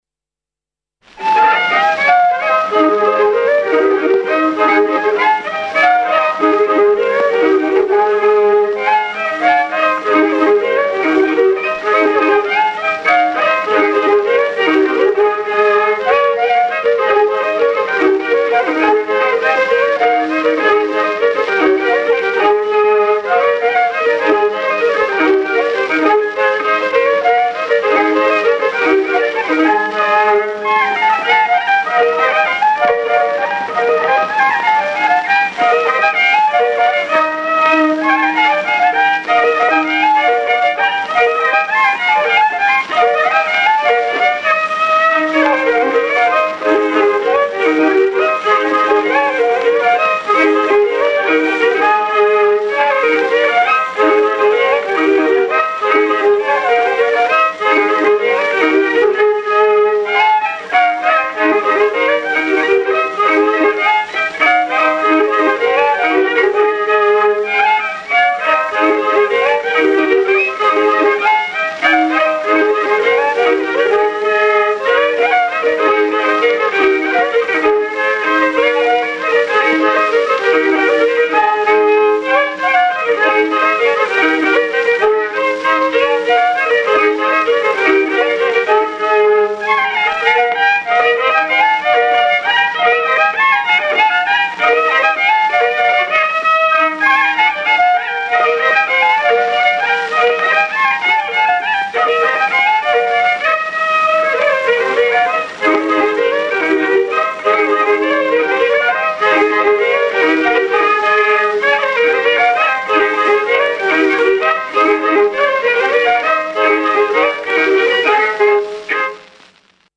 I viiul
II viiul